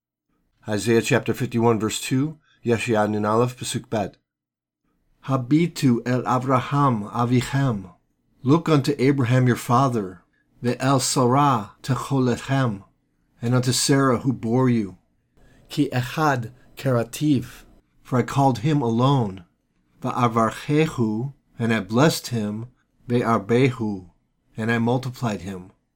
Isaiah 51:2 reading (click for audio):
Isaiah 51:2 Hebrew lesson